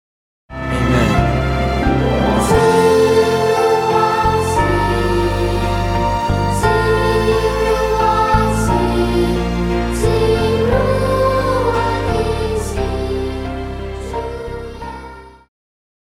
Christian
Pop chorus,Children Voice
Band
Hymn,POP,Christian Music
Voice with accompaniment
為了淺顯易懂，除了把現代樂風融入傳統聖詩旋律，針對部份艱澀難懂的歌詞，也稍作修飾，儘可能現代化、口語化；